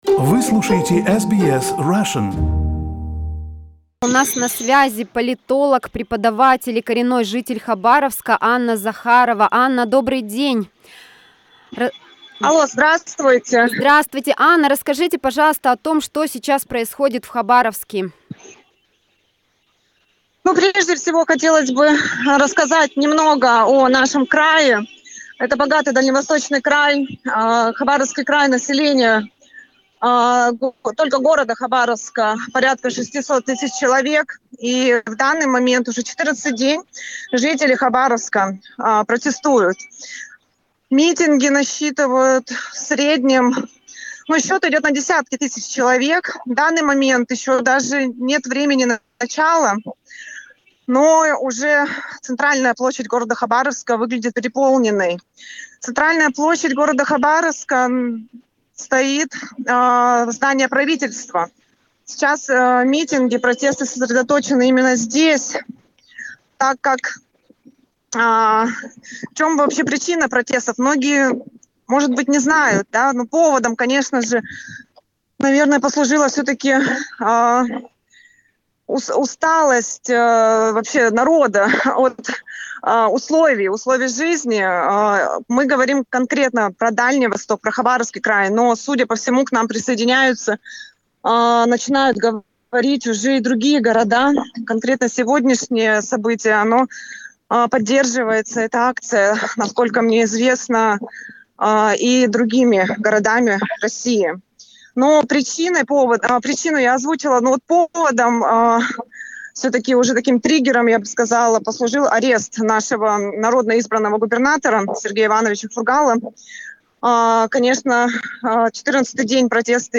Protests in Khabarovsk: live interview